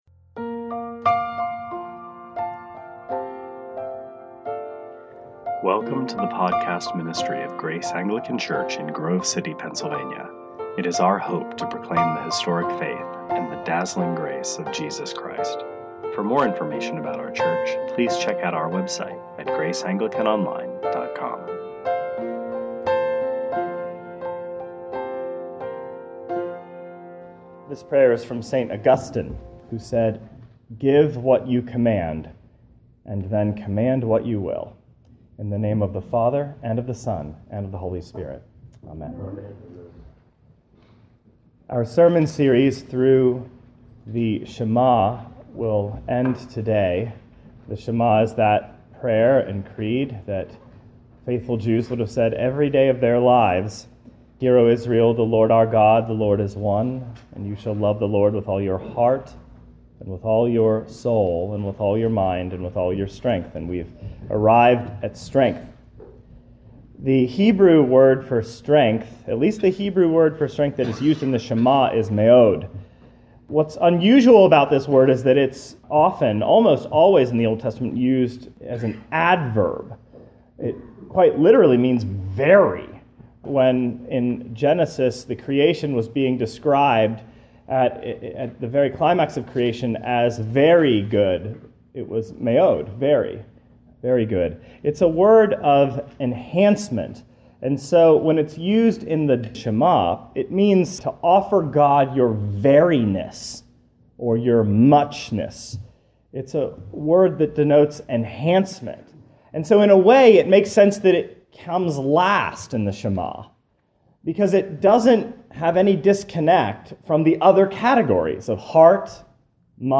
2018 Sermons Emerald City, Arise!